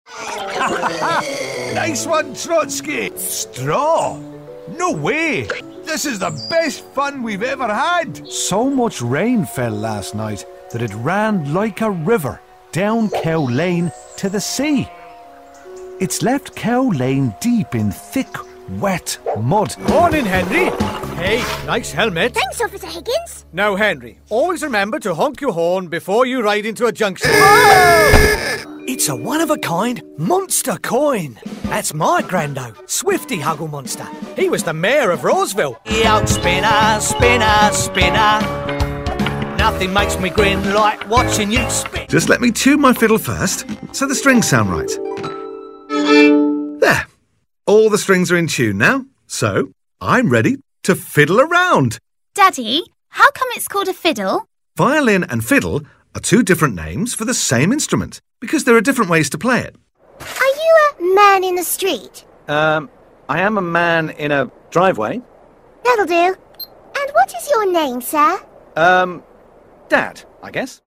Animation
English (British)
Middle-Aged
Senior
Deep
WarmComfortingFriendlyApproachableExcitedExperienced